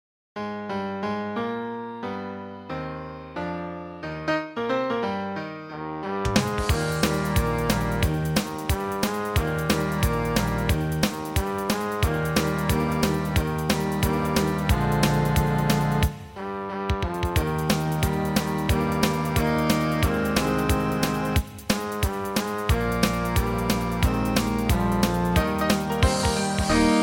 When the Saints (Horn Eb)